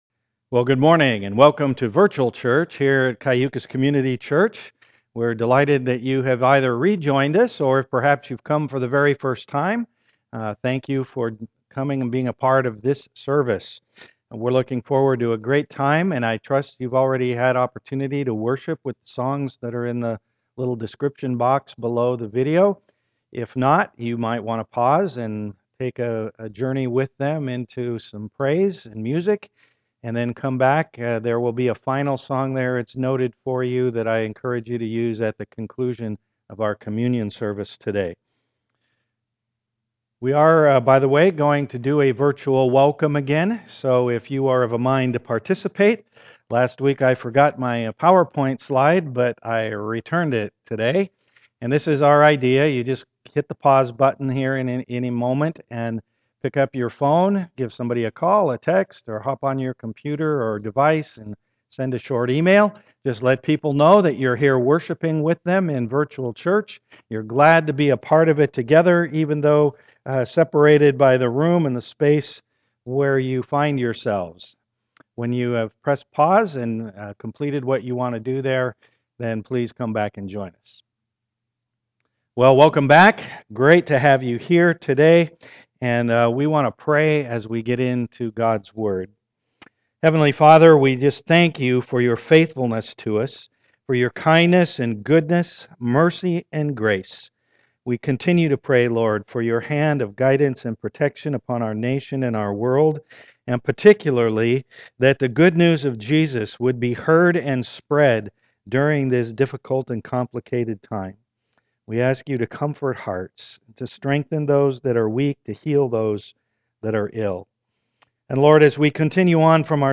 Psalm 62 Service Type: am worship Pleas e note